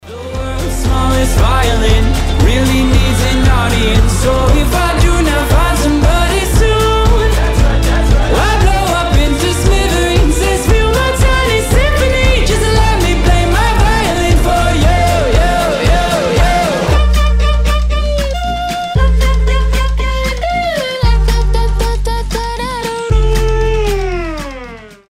• Качество: 320, Stereo
скрипка